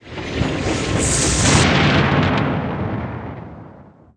whirlpoolEnd.wav